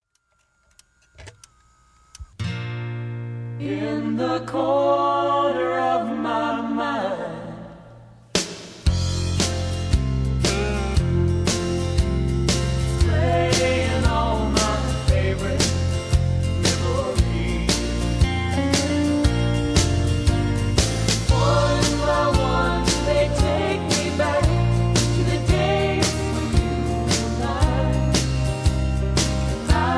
backing tracks, karaoke, sound tracks, studio tracks, rock